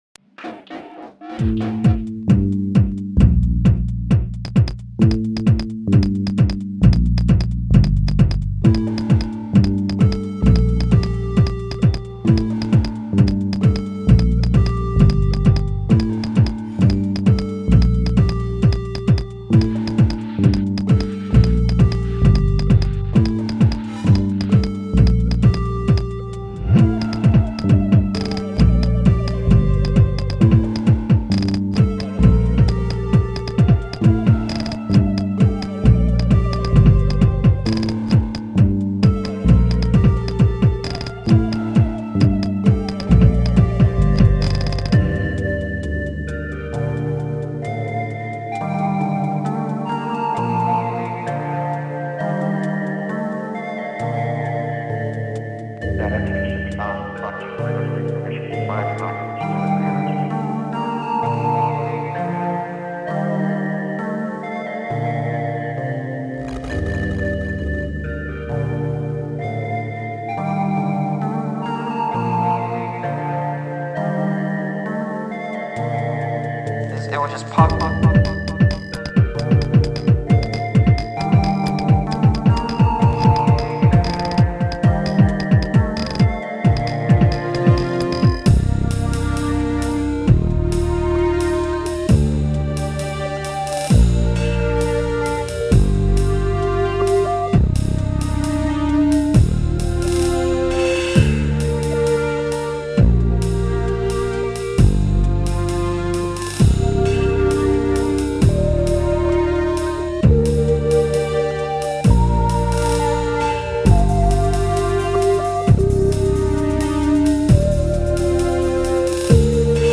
All made with a combo of records, Yamaha SU200 sampler, Casio MT68, Yamaha PSS 470, and Cakewalk Home Studio via a M-Audio Delta 44.